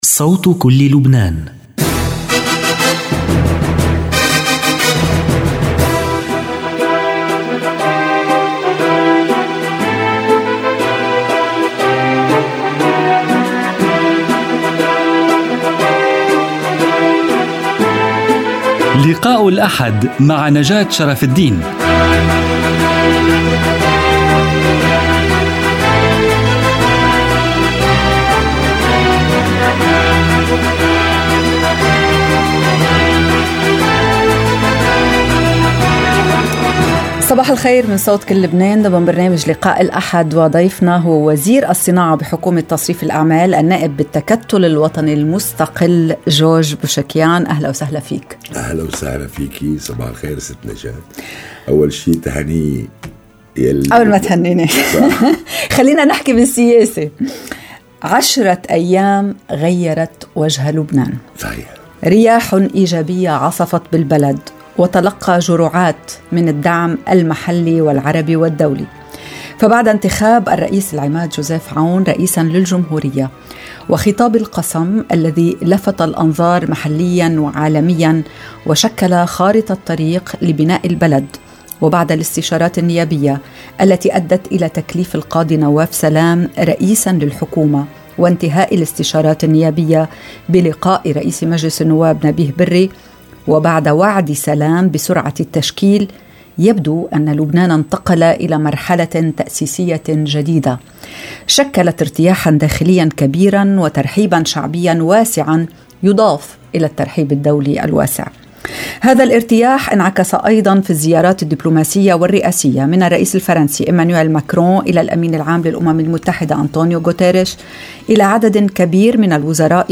لقاء الأحد وزير الصناعة في حكومة تصريف الأعمال جورج بوشيكيان Jan 19 2025 | 00:57:21 1x 00:00 / 00:57:21 Subscribe Share RSS Feed Share Link Embed